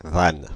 Ääntäminen
Ääntäminen Paris: IPA: [van] France (Île-de-France): IPA: /van/ Haettu sana löytyi näillä lähdekielillä: ranska Käännös Ääninäyte Substantiivit 1. valve 2. floodgate 3. gate US 4. wisecrack Suku: f .